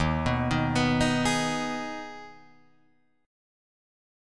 D#sus4#5 chord